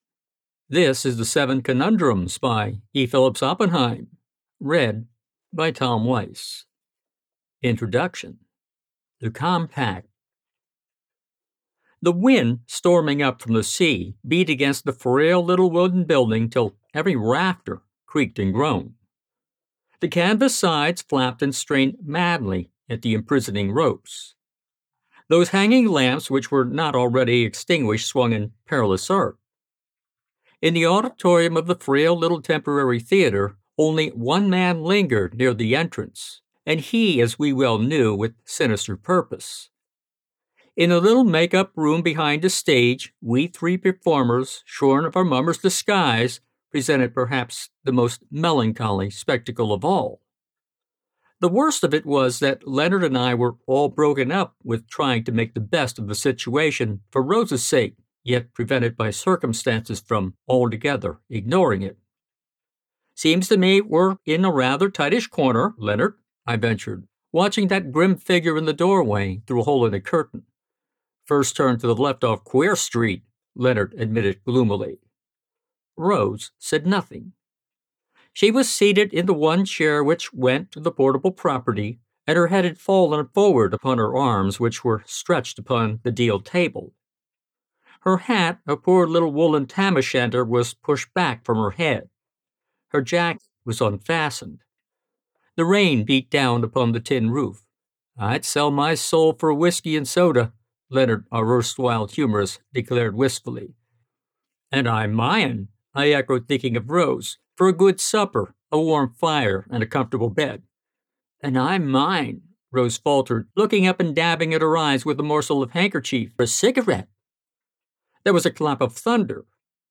First Chapter FREE Sample